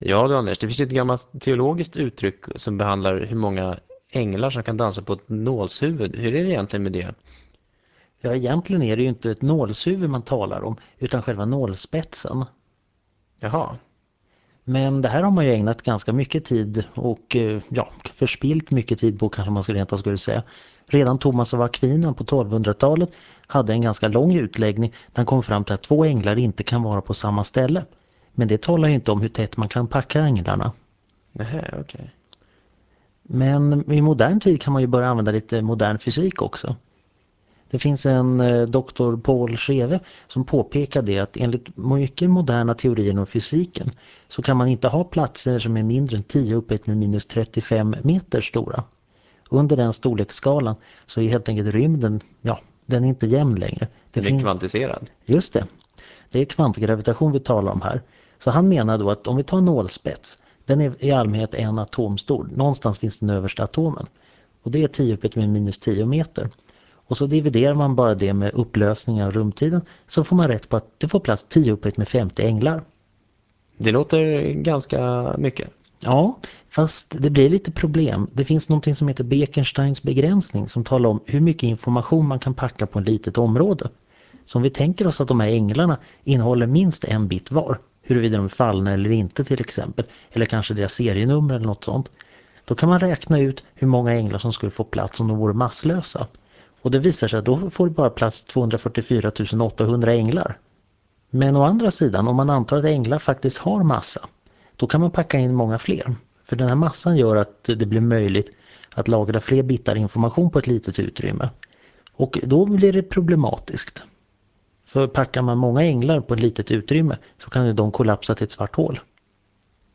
Föredraget handlar om fysik och sänds i Etervåg.